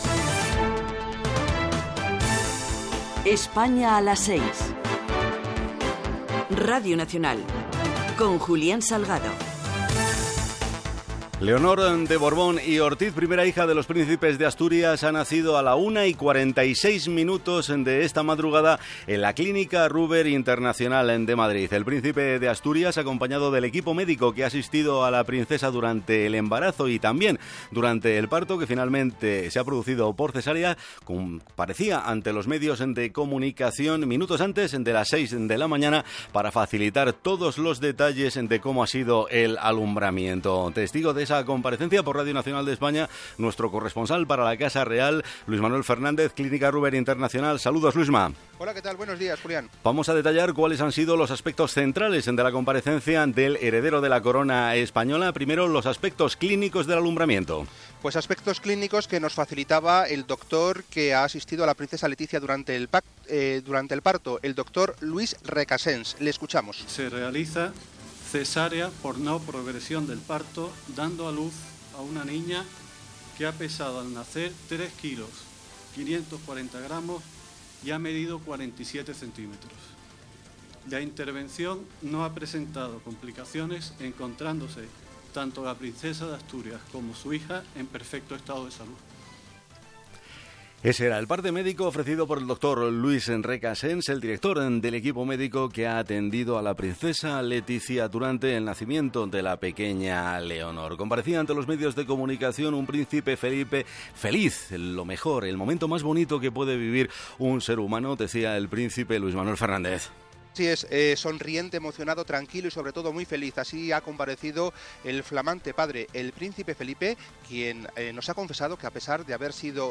Careta del programa, informació del naixement de Leonor de Borbón Ortiz, a Madrid, filla dels Príceps d'Astúries
Informatiu